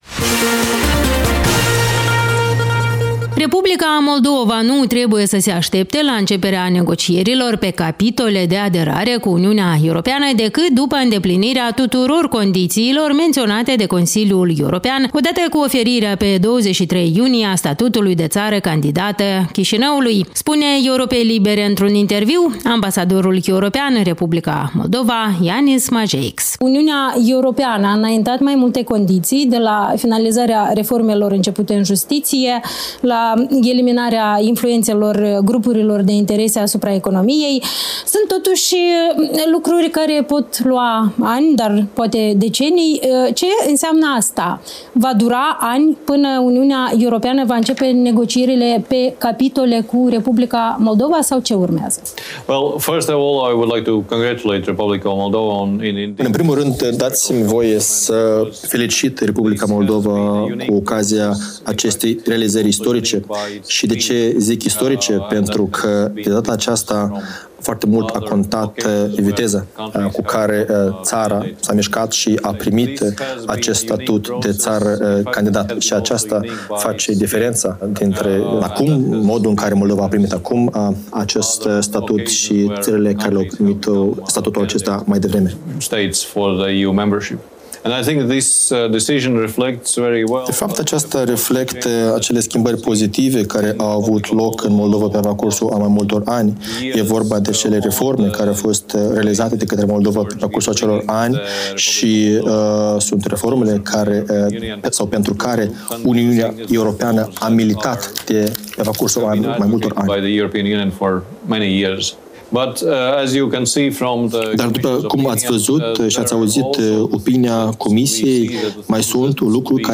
Ambasadorul Uniunii Europene la Chișinău, Jānis Mažeiks, a declarat, într-un interviu pentru radio Europa Liberă, că UE va începe negocierile de aderare pe capitole cu Moldova doar după îndeplinirea tuturor condițiilor înaintate de Consiliul...